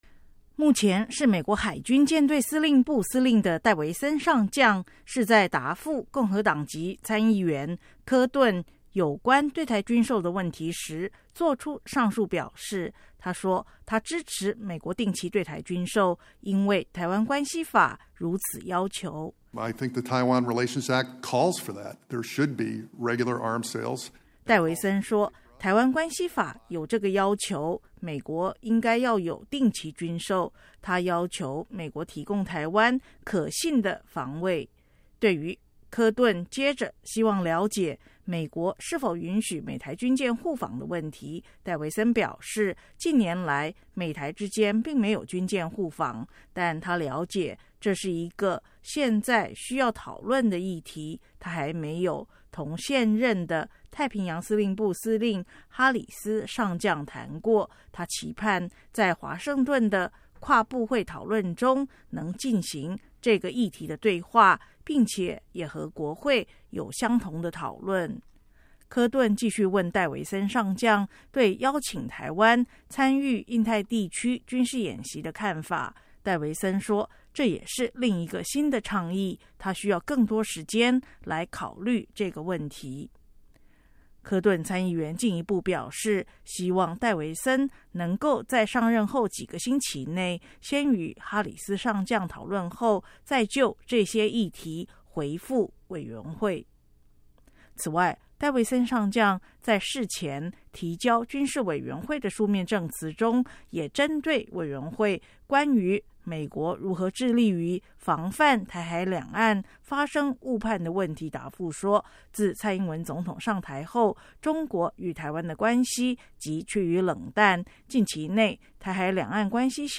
海军上将菲利普·戴维森在国会听证上